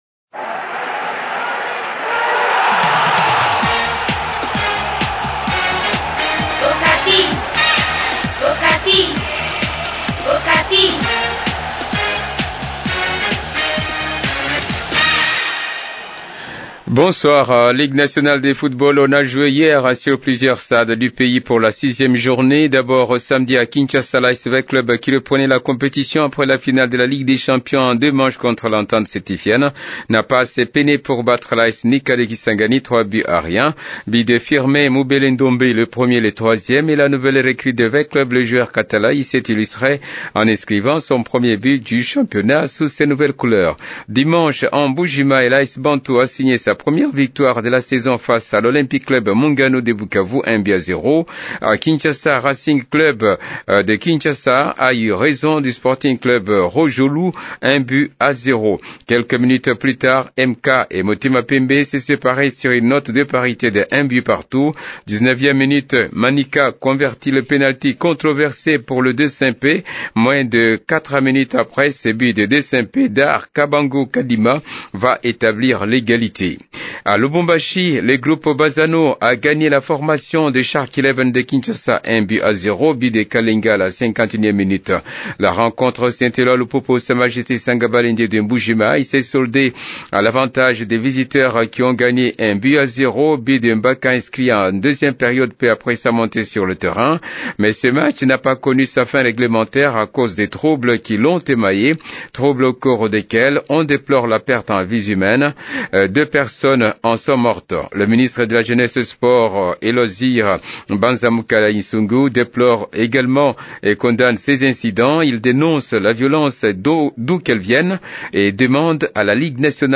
Journal des sports du 24 novembre 2014
journal-des-sports-site16.mp3